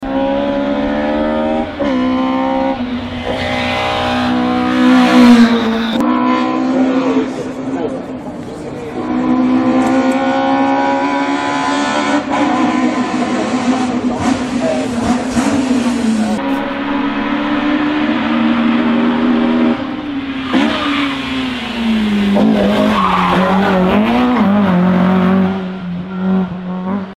Ah the legendary Toyota AE86 sound effects free download
Ah the legendary Toyota AE86 (Levin) with a tuned 4age engine and probably open ITBs from the sound of it.